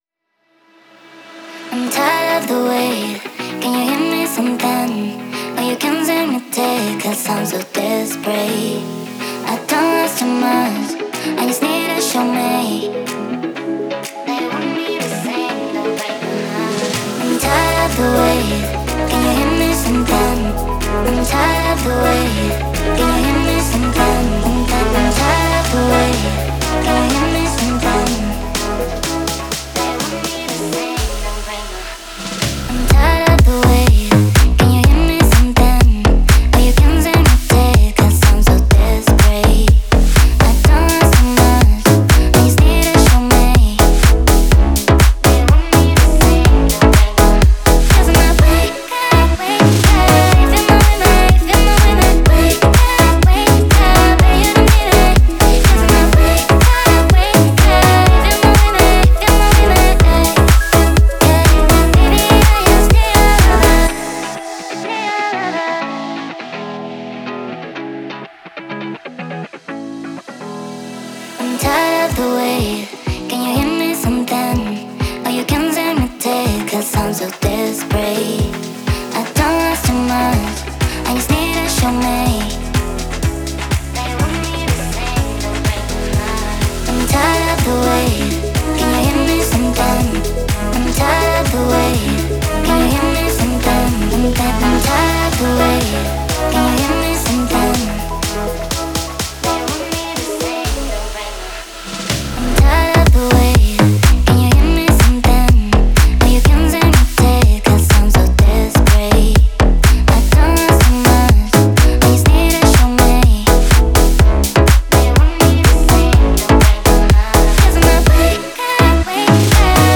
это энергичный трек в жанре поп-рок